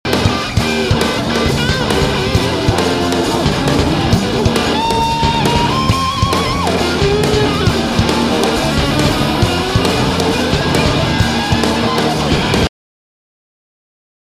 git,voc,elektrik
bass
drums